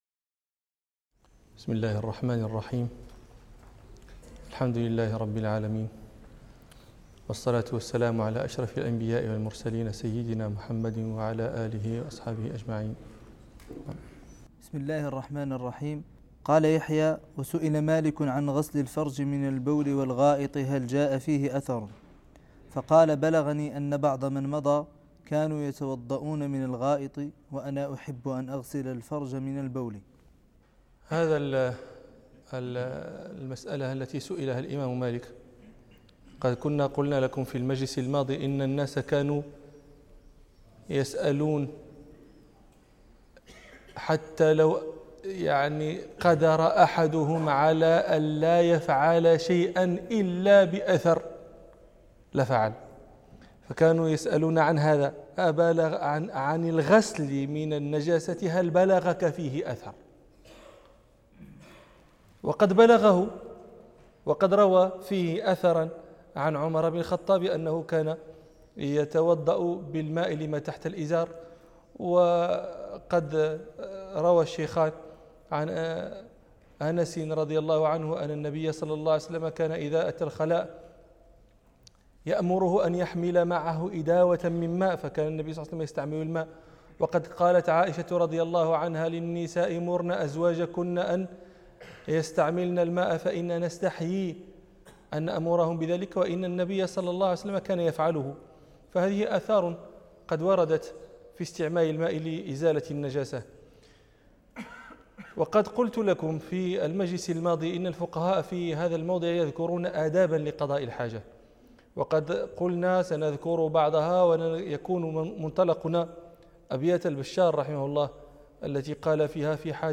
الدرس الخامس والخمسون من دروس كرسي الإمام مالك